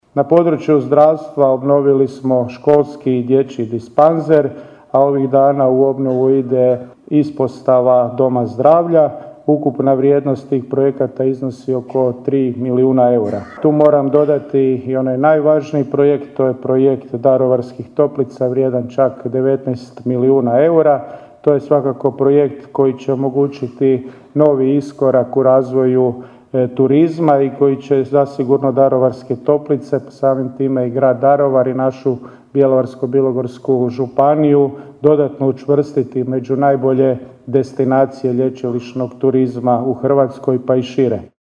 Svečana sjednica u povodu Dana Grada Daruvara održana je u dvorani Gradskog kina Pučkog otvorenog učilišta.